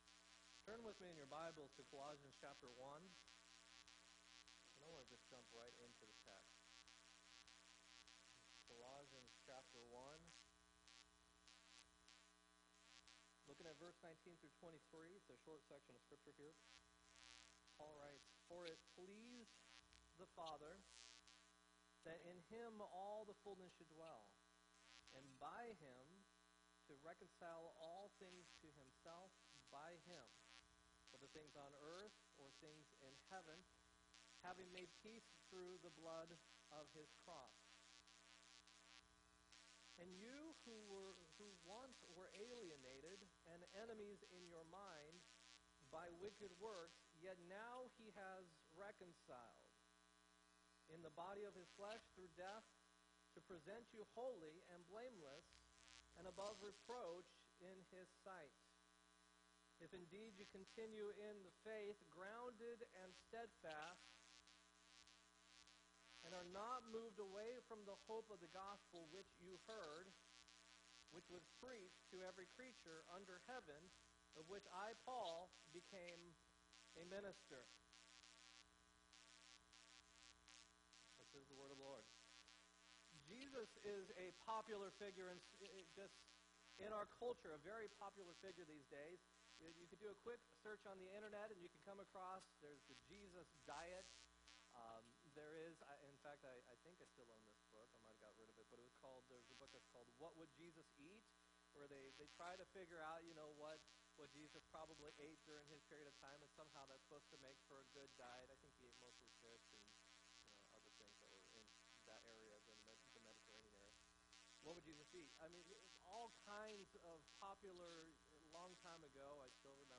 4-8-17 sermon
4-8-17-sermon.m4a